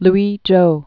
(lwējō)